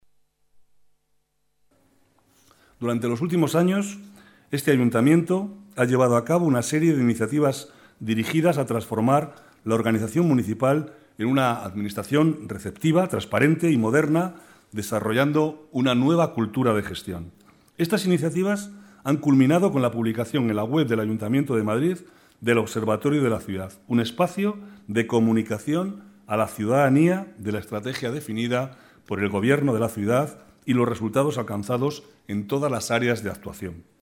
Nueva ventana:Declaraciones del vicealcalde, Manuel Cobo: Observatorio de la Ciudad